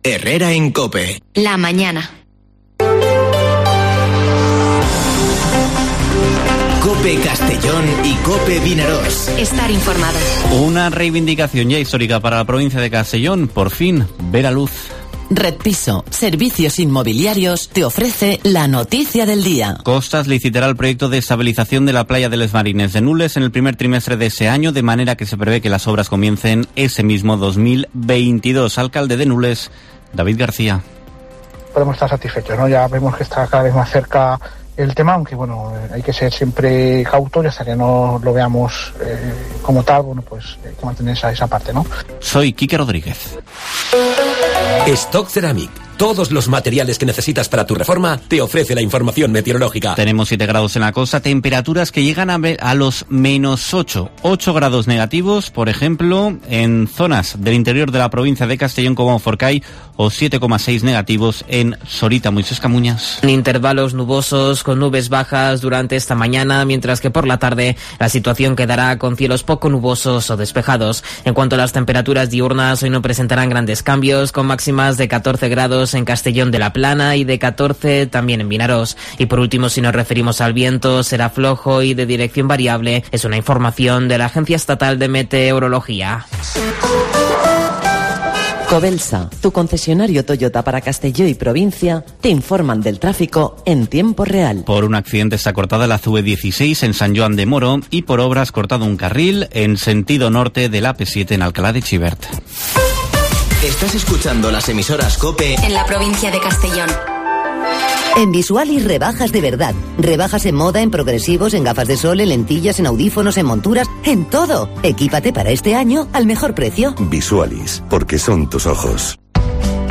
Informativo Herrera en COPE en la provincia de Castellón (13/01/2022)